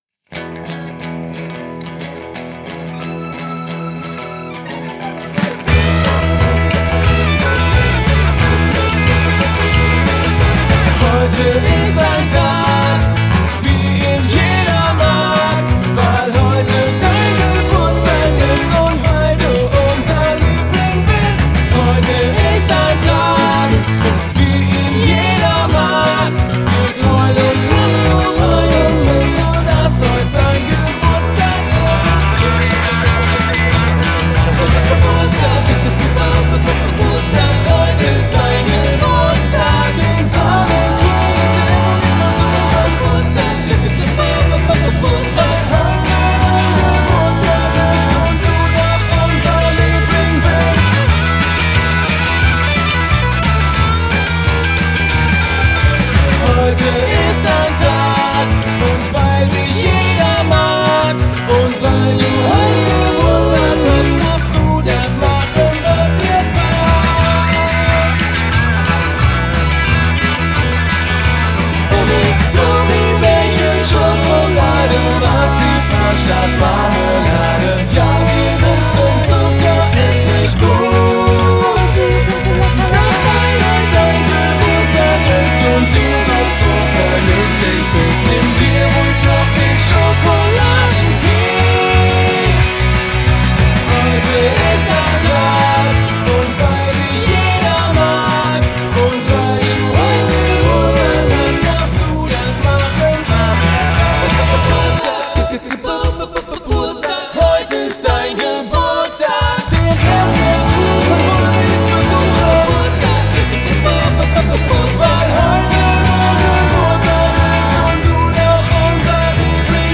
Kinderparty
Die Melodie ist eingänglich, der Text simpel und herzlich.